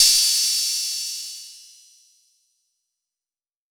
6CRASH 2.wav